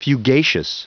Prononciation du mot fugacious en anglais (fichier audio)
Prononciation du mot : fugacious